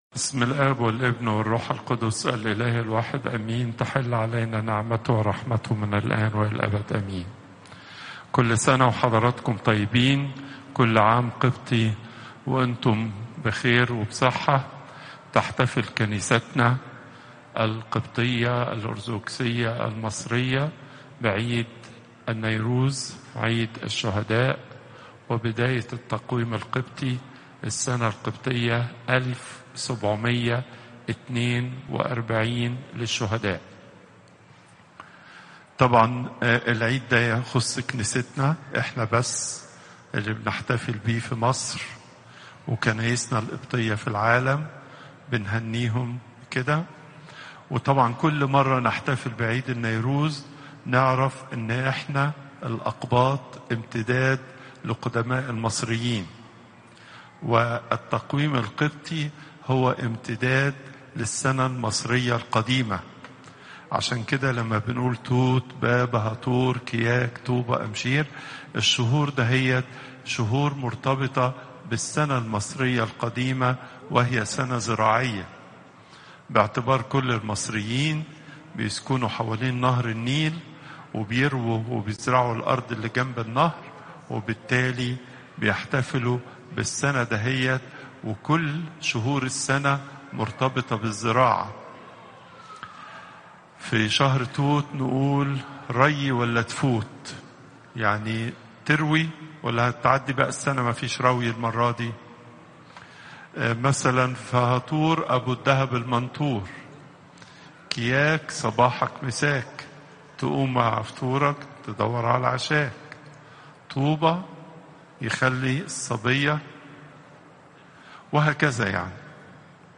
Popup Player Download Audio Pope Twadros II Thursday, 11 September 2025 25:09 Pope Tawdroes II Weekly Lecture Hits: 58